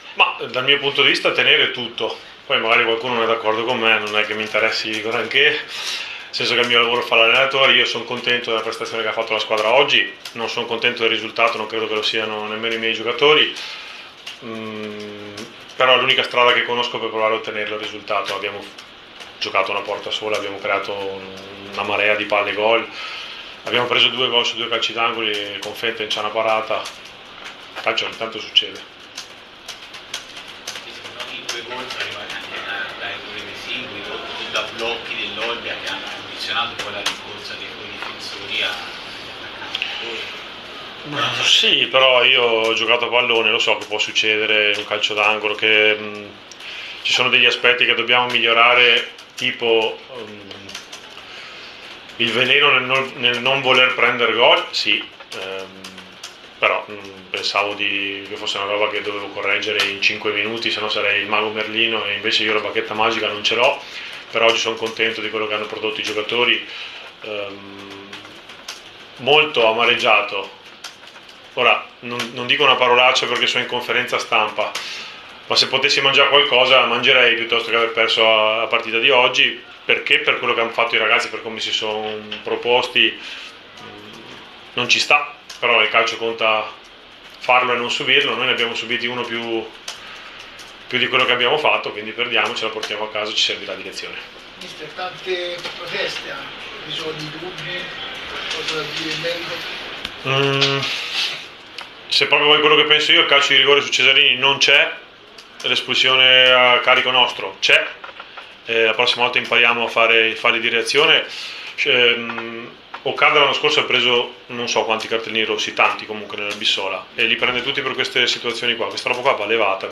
in conferenza stampa